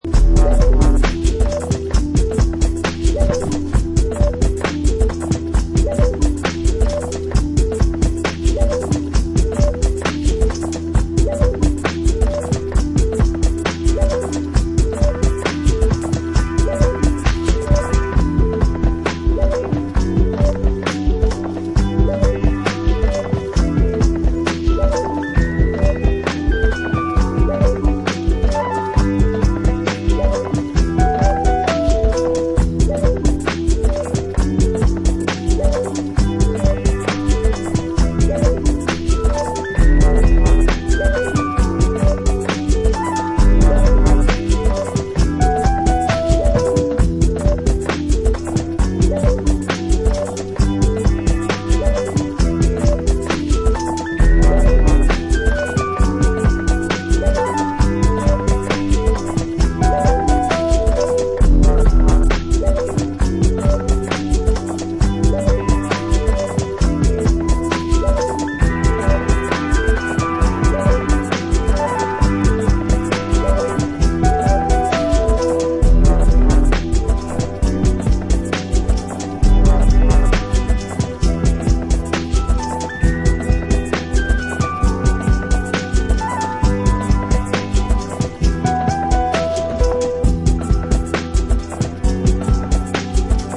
a downtempo track wich uses kraut influences